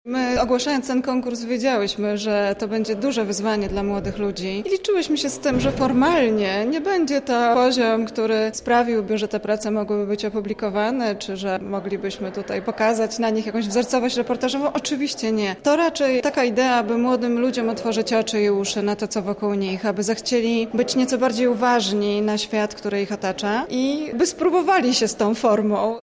reportaż